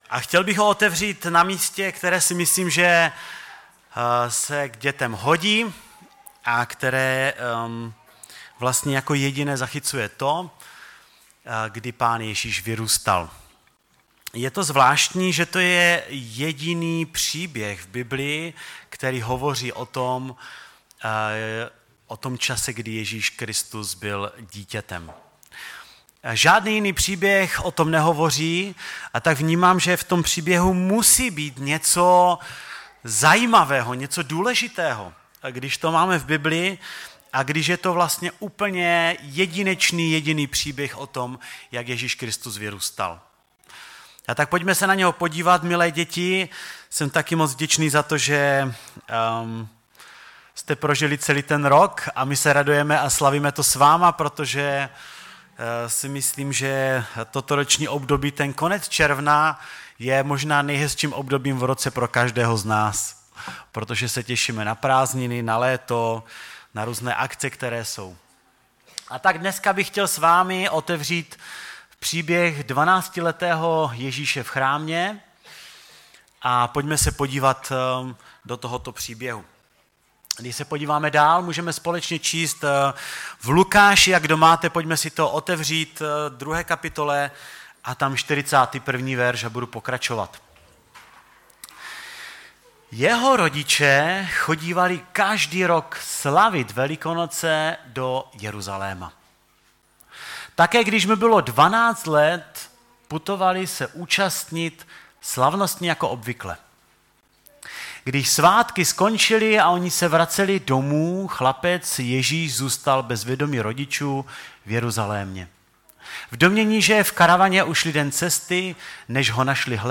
ve sboře Ostrava-Radvanice.
Kázání